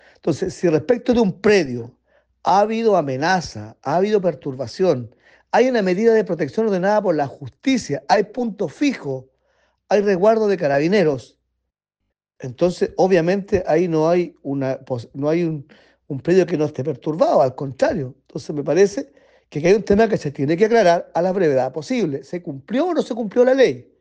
El diputado de la UDI, Henry Leal, indicó que según las condiciones del predio, si parece estar “perturbado”.